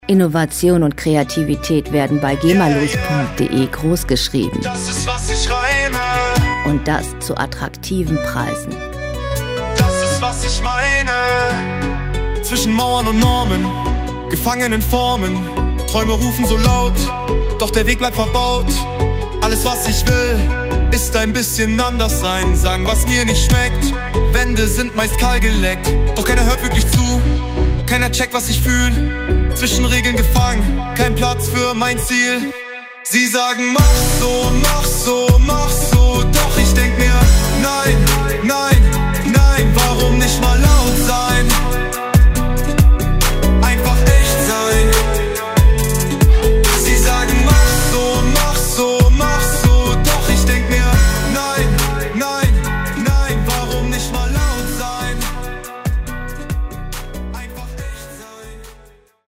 Pop Musik aus der Rubrik: "Popwelt Deutsch"
Musikstil: Deutschrap
Tempo: 70 bpm
Tonart: H-Moll
Charakter: anklagend, rebellisch
Instrumentierung: Rapper, Synthesizer, Harfe